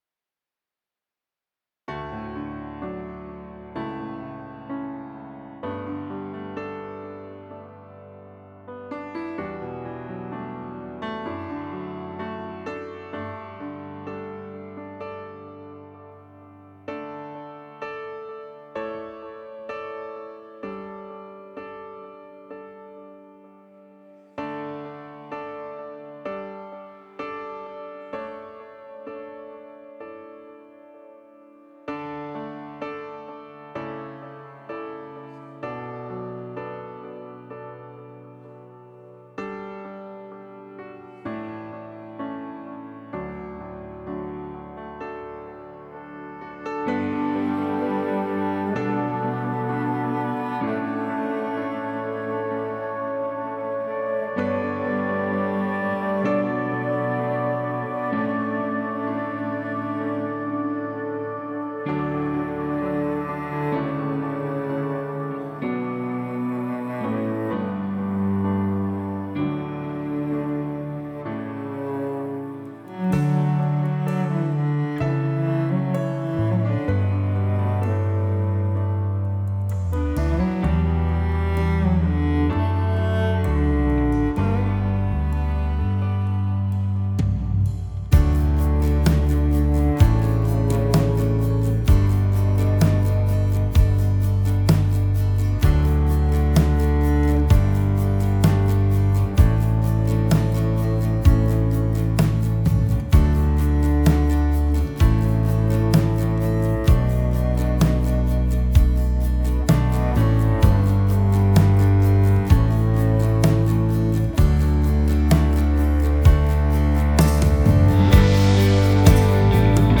Tempo: Langzaam